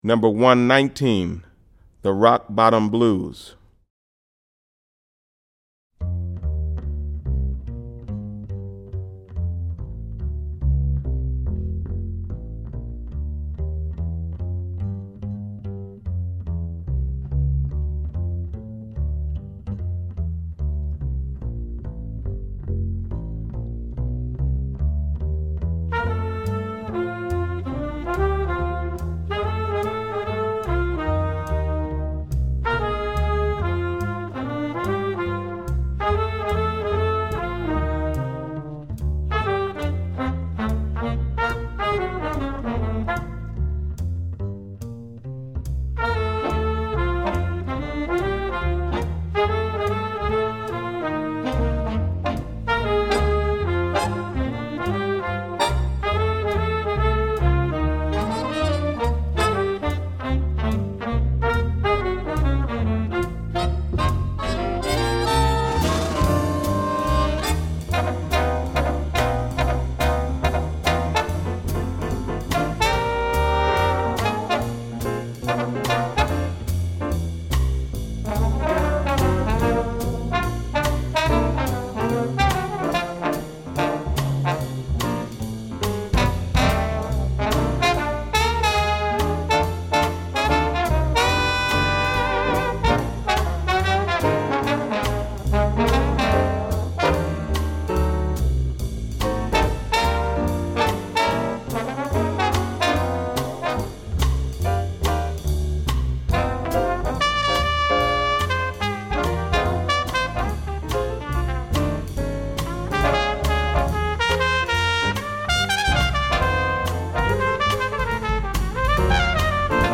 • 5 Saxophones
• 4 Trumpets
• 4 Trombones
• Vibraphone
• Guitar
• Piano
• Bass
• Drums